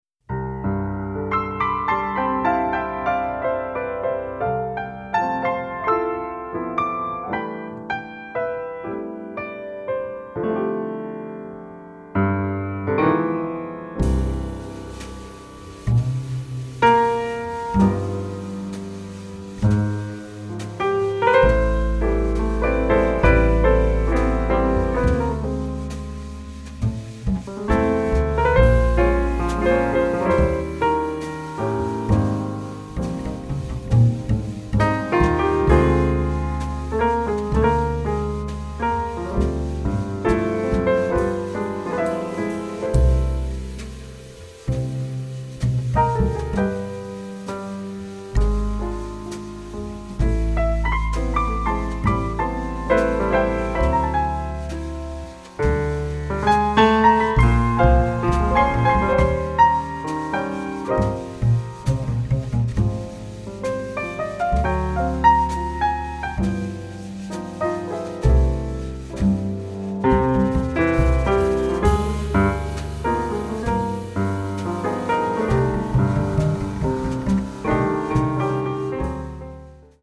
tenor sax and vocals
piano
bass
-drums